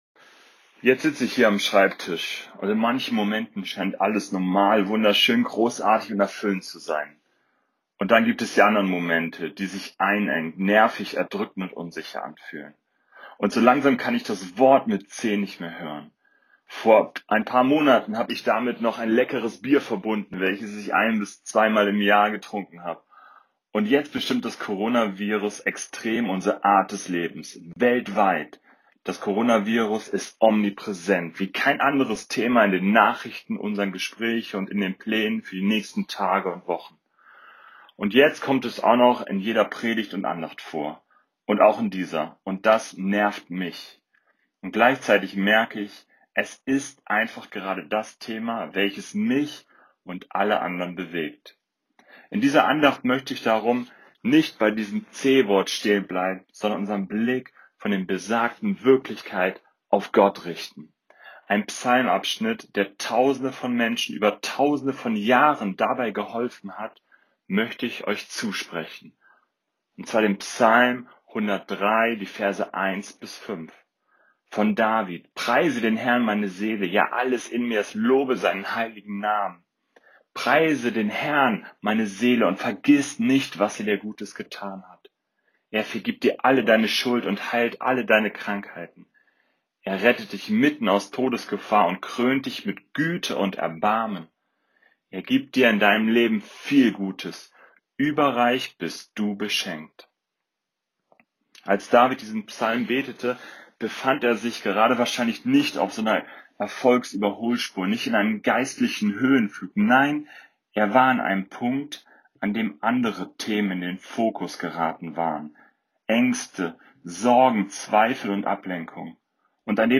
Corona Andacht zu Psalm 103, 1-5